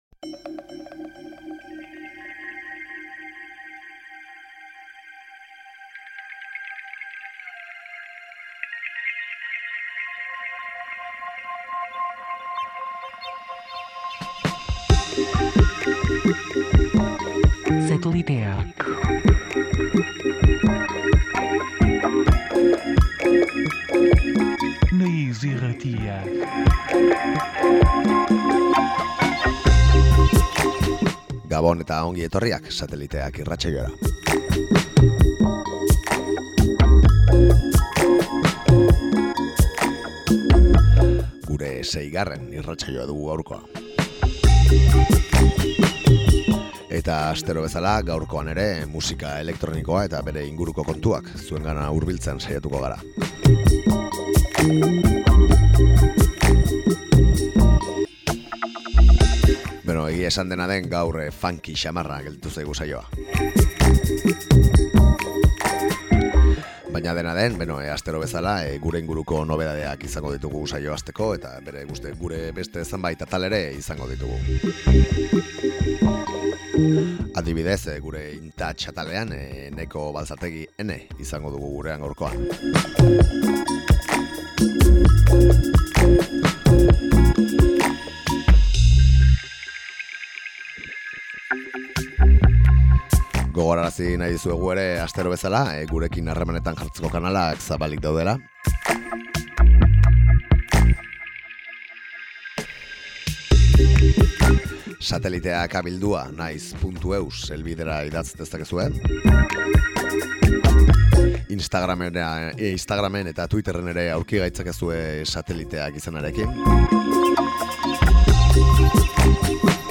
Urteko azken ‘Sateliteak’ saioa da hau. Inoiz baino funky-agoa izan da azken bidaia hau, noski, elektronika alde batera utzi gabe.